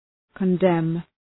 {kən’dem}
condemn.mp3